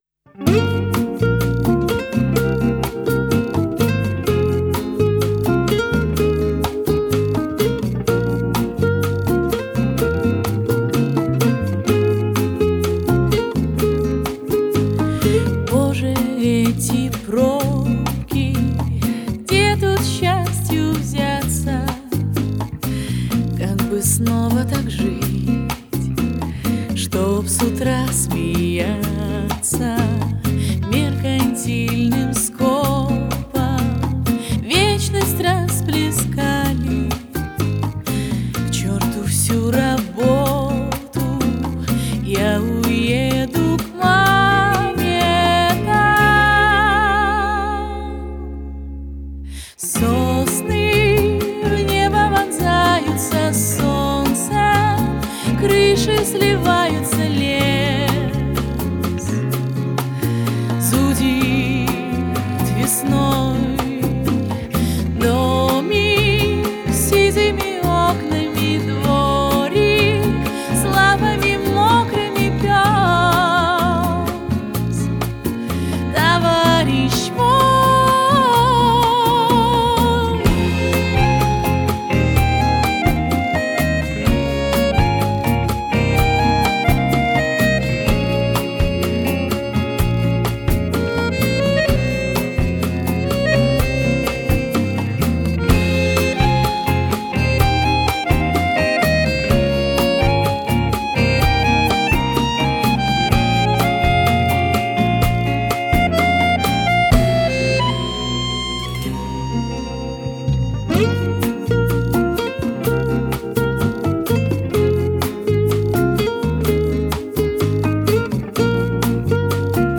Этническая музыка